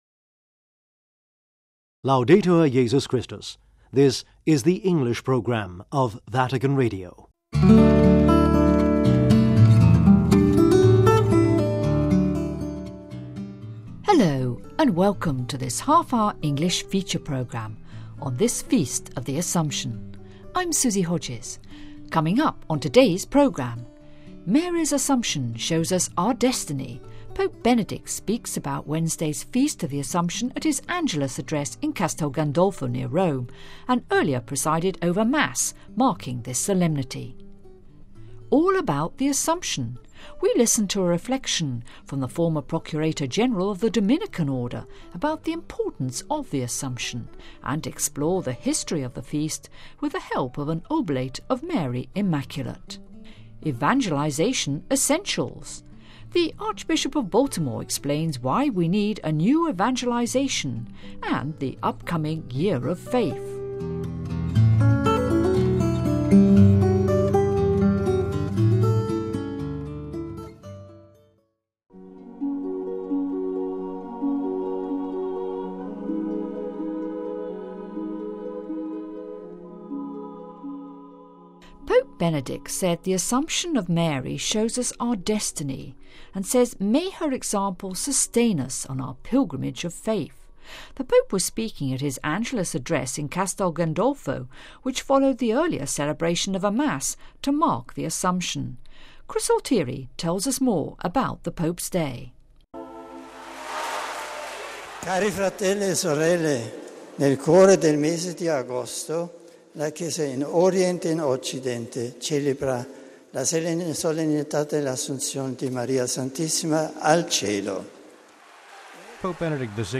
MARY’S ASSUMPTION SHOWS US OUR DESTINY : Pope Benedict speaks about Wednesday’s Feast of the Assumption at his Angelus address in Castelgandolfo and presides over a Mass marking this Solemnity....